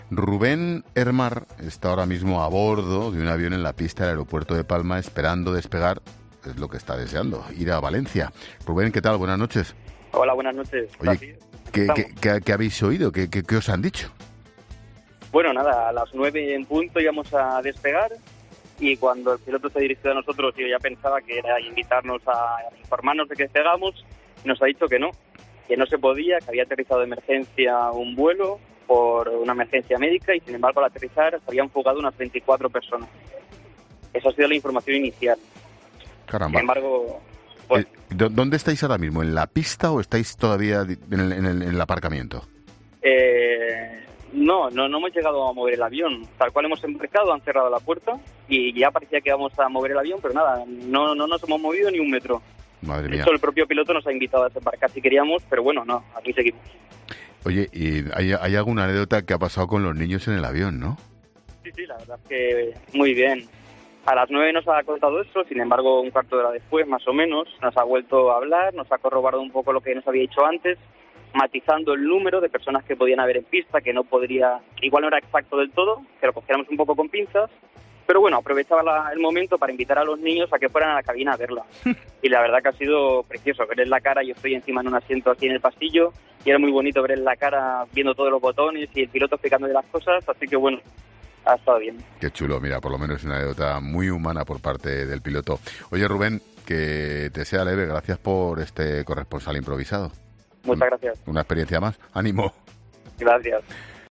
pasajero en uno de los aviones paralizados